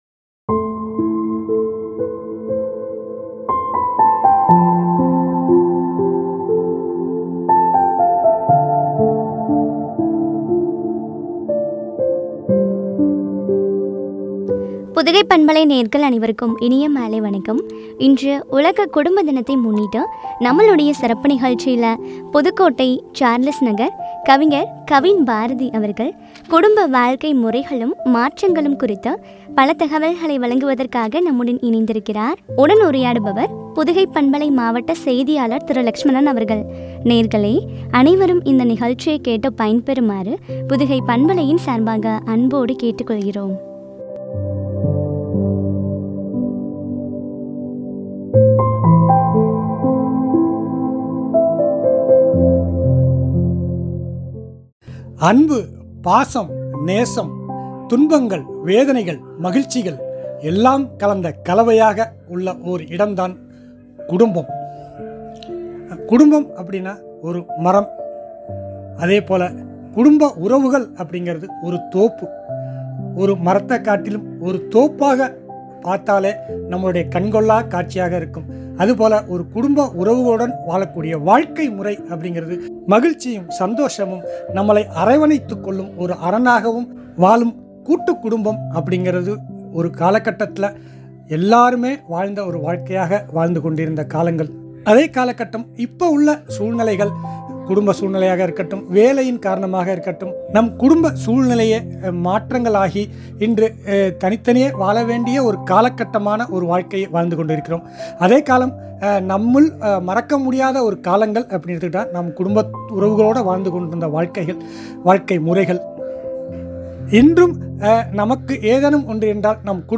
மாற்றங்களும் பற்றிய உரையாடல்.